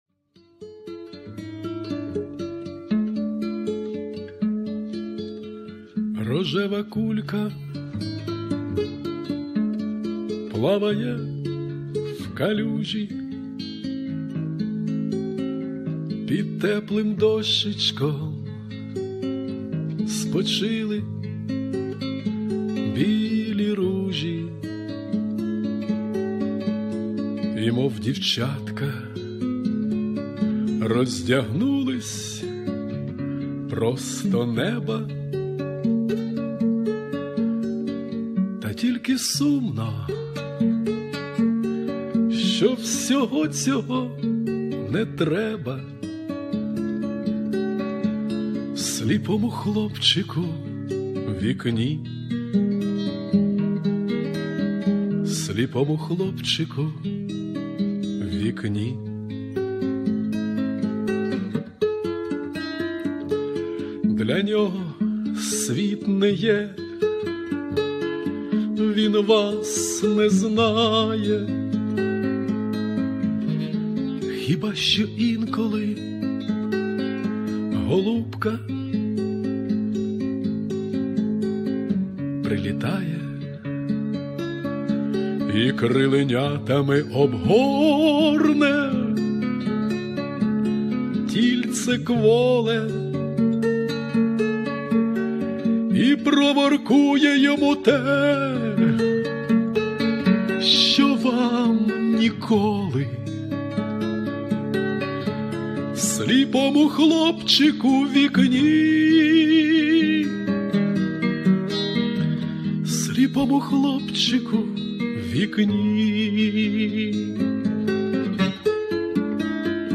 Авторська пісня
Сумна пісня, але виконана з таким душевним, надривним почуттям.